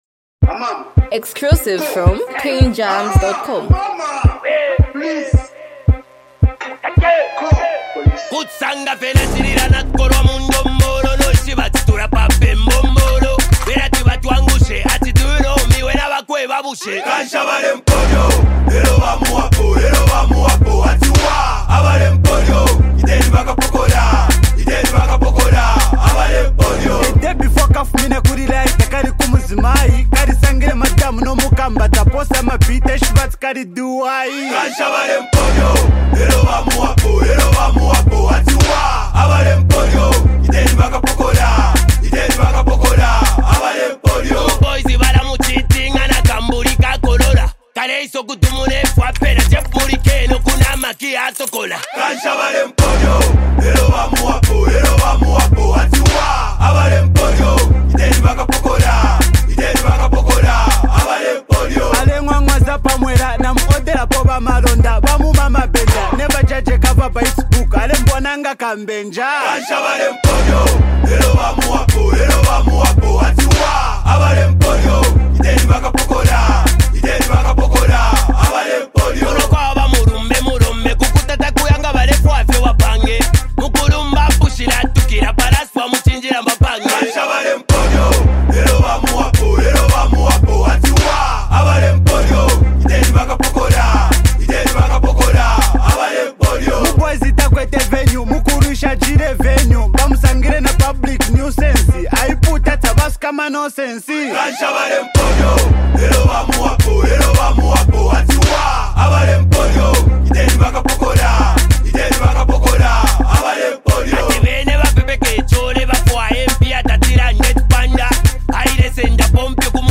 Multi talented duo
hype, street vibes, and a powerful hook with unique rap flow